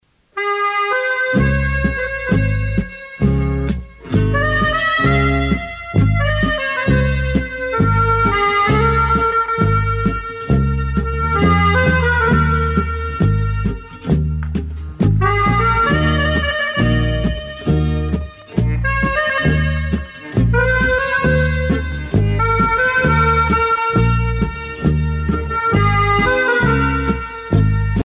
tango musette
Tempo Grande vitesse
Pièce musicale éditée